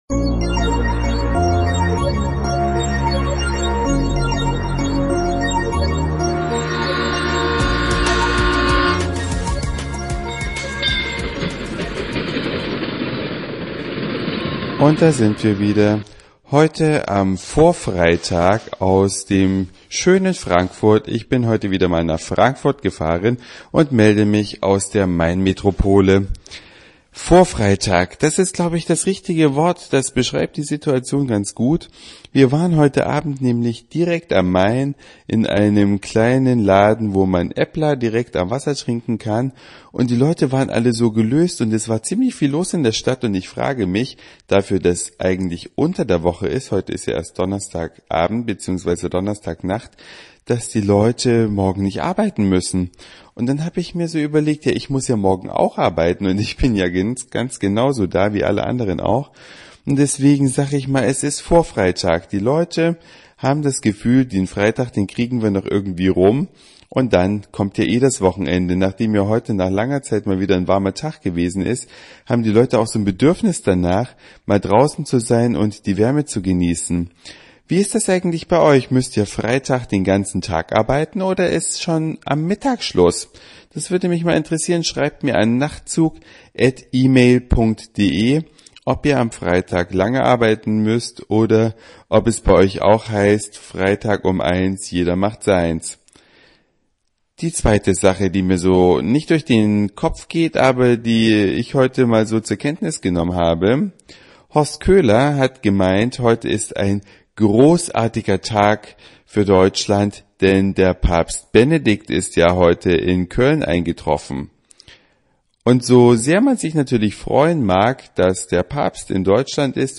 Eine Reise durch die Vielfalt aus Satire, Informationen, Soundseeing und Audioblog.
Vorfreitag in der Mainmetropole Frankfurt.